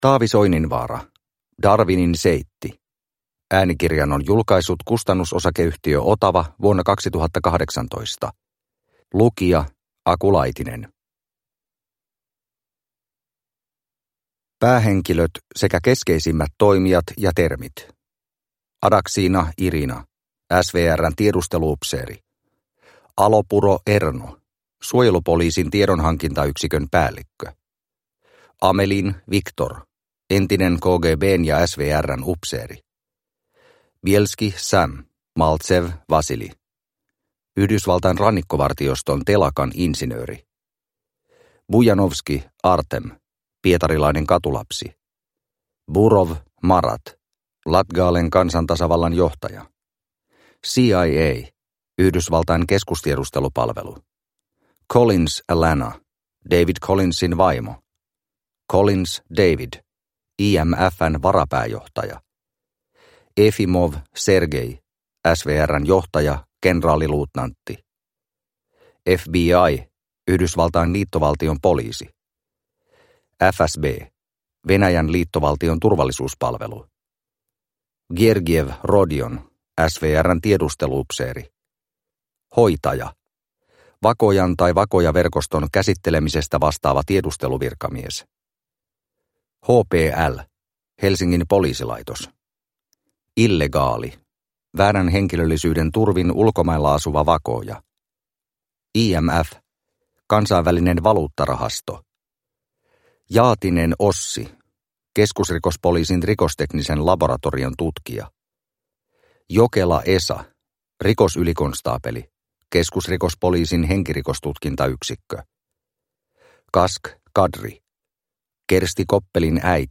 Darwinin seitti – Ljudbok – Laddas ner